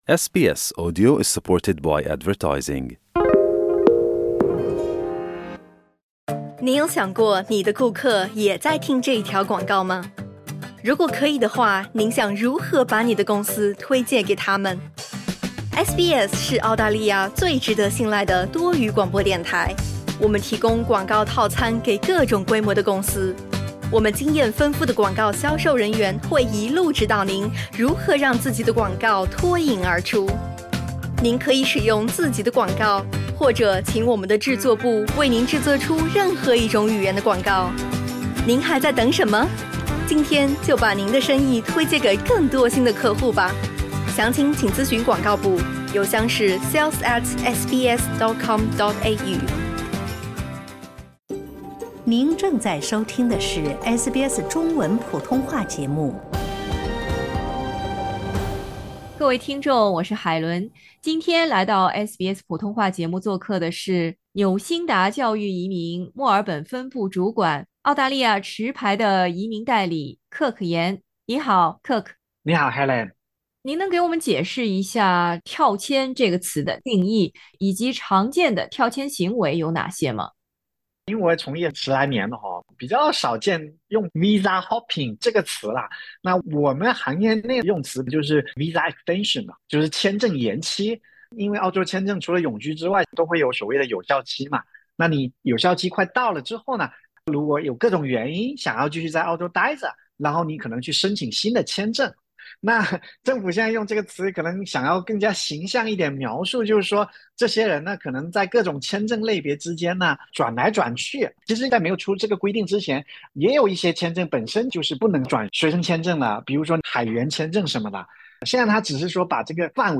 （采访内容仅为嘉宾个人建议，仅供参考。）